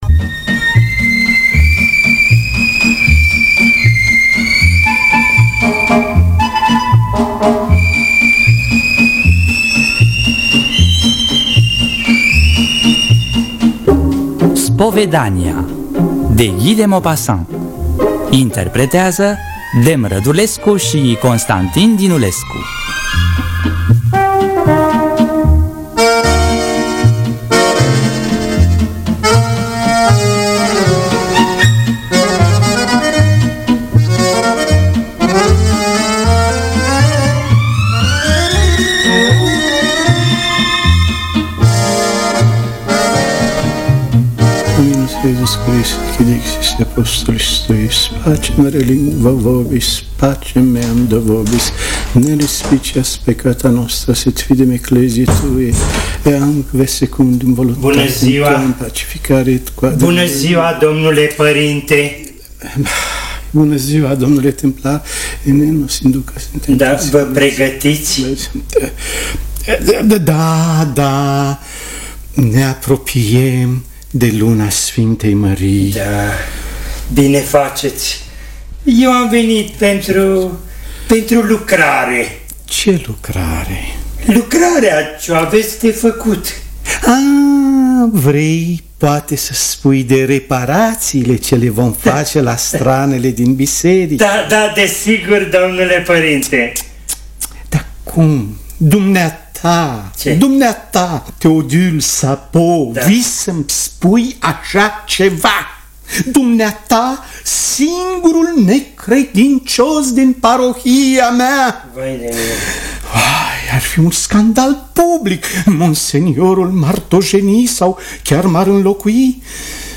În distribuţie: Dem Rădulescu, Constantin Dinulescu.